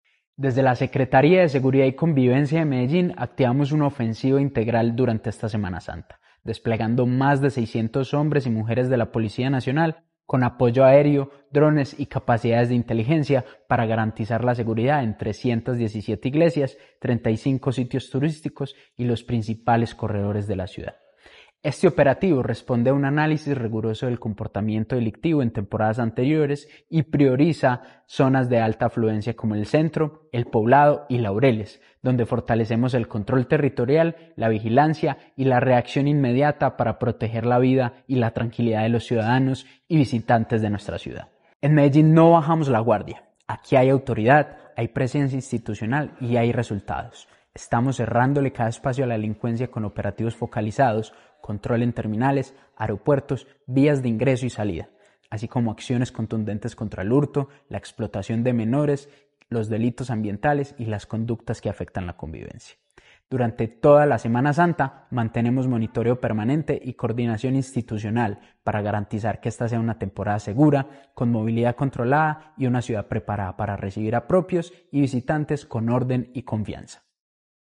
Declaraciones del subsecretario Operativo de la Secretaría de Seguridad, Sebastián Acosta
Declaraciones-del-subsecretario-Operativo-de-la-Secretaria-de-Seguridad-Sebastian-Acosta.mp3